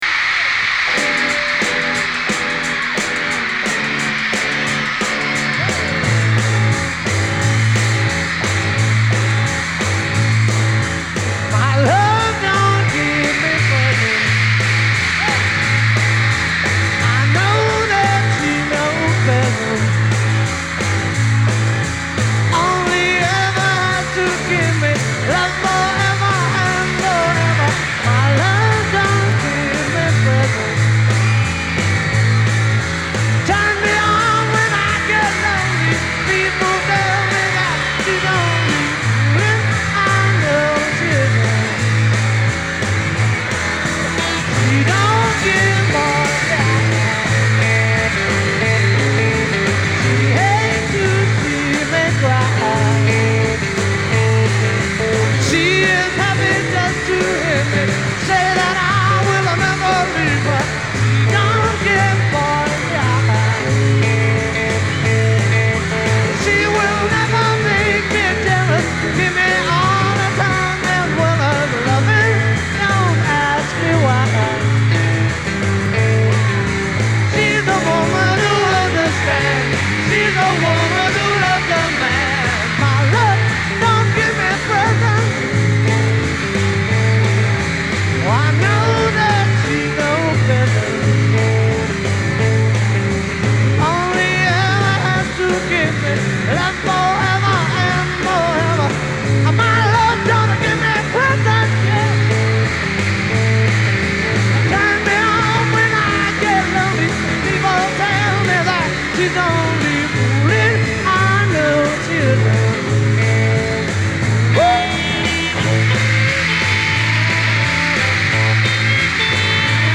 virtually unlistenable
crowd noise